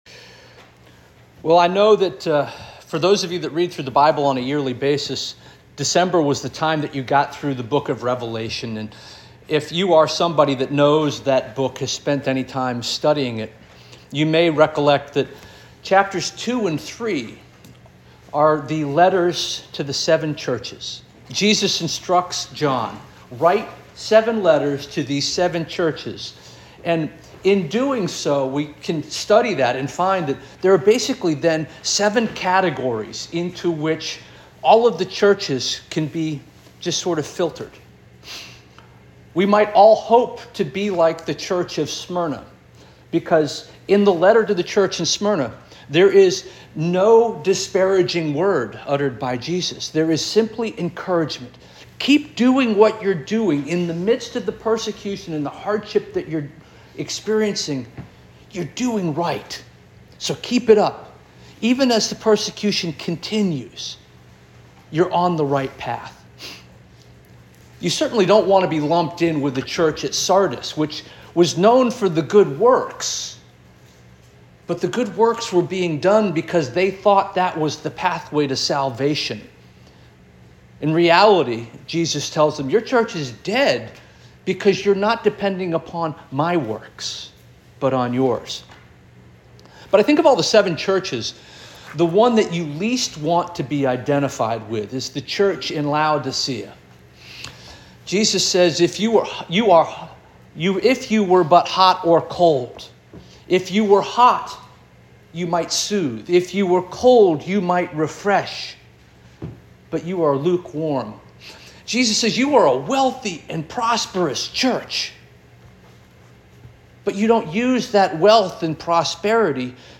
January 26 2025 Sermon - First Union African Baptist Church